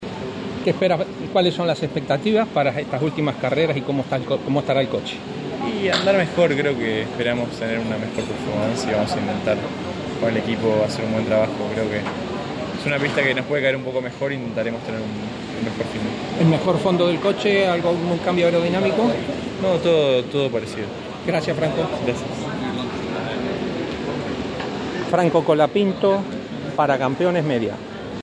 ESCUCHÁ A FRANCO COLAPINTO CON CAMPEONES